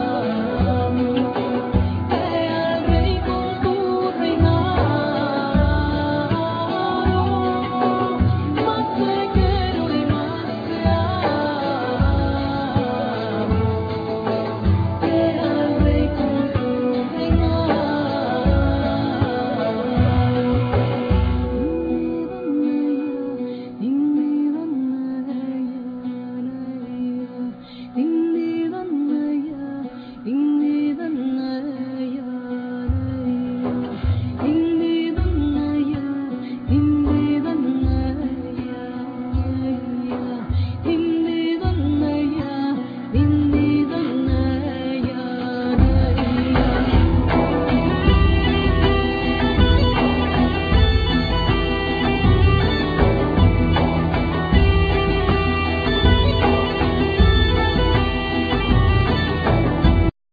Viella,Rebec,Cello
Chorus